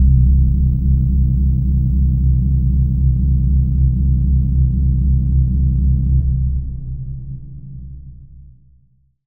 Dl Bass.wav